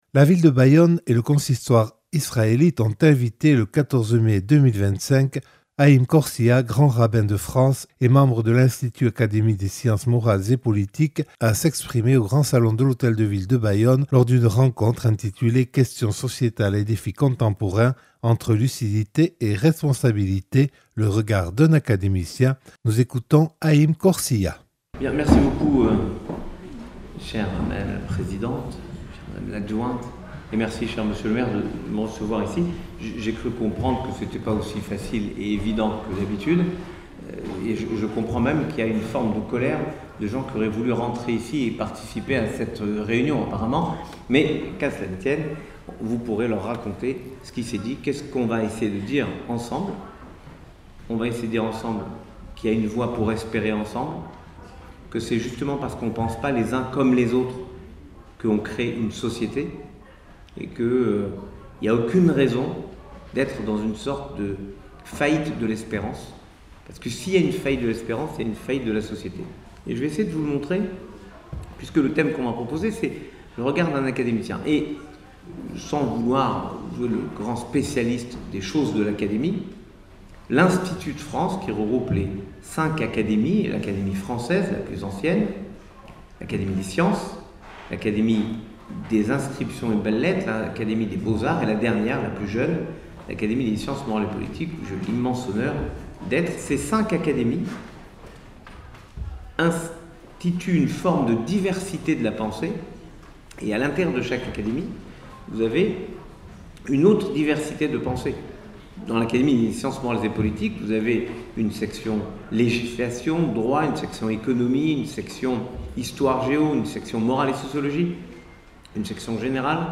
Voici de larges extraits de la rencontre avec Haïm Korsia, Grand-Rabbin de France et membre de l’Institut – Académie des Sciences Morales et Politiques. Rencontre organisée par la Ville de Bayonne et le Consistoire Israëlite le 14 mai 2025 dans le Grand Salon de l’Hôtel de Ville de Bayonne.